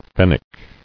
[fen·nec]